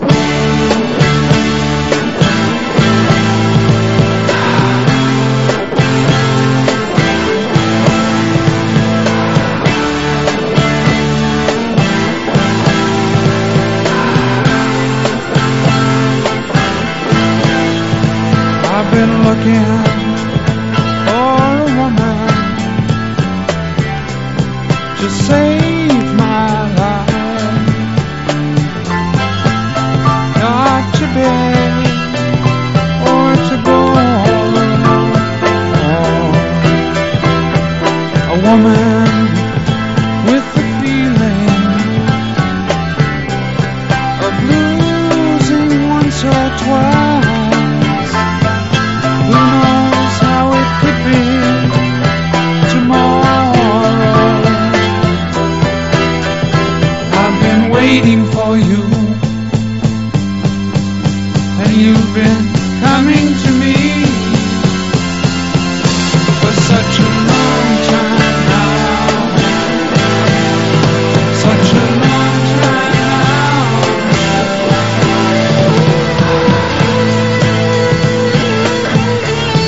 ROCK / 60'S / PSYCHEDELIC ROCK / GARAGE ROCK / FREAK BEAT